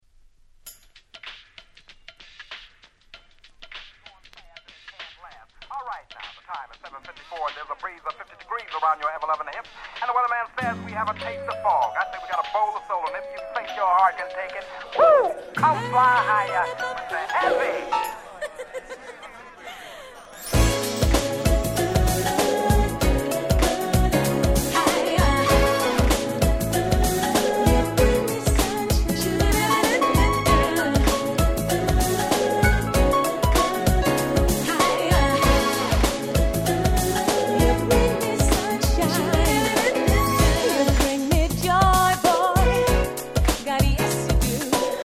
UK R&B名曲中の名曲。
とにかく洗練された爽やかな素晴らしい1曲です！